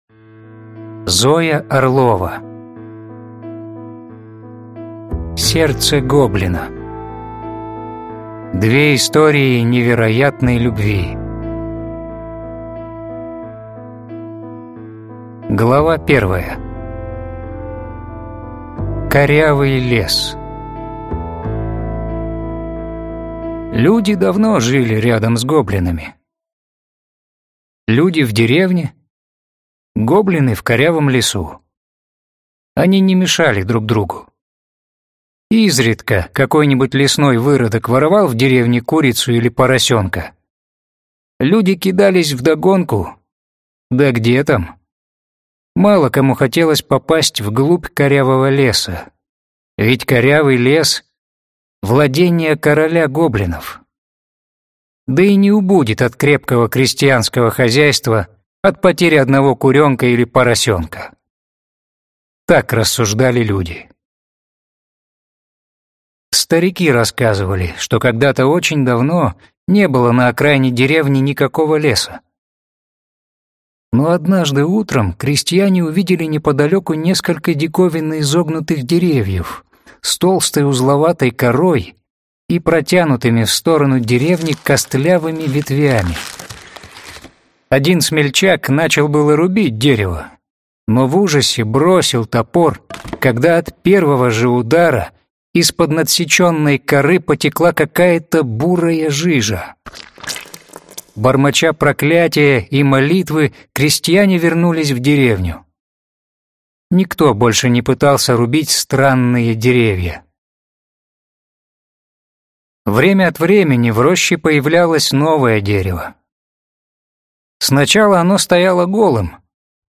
Аудиокнига Сердце гоблина. Две истории невероятной любви | Библиотека аудиокниг